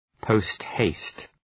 Προφορά
{‘pəʋst,heıst}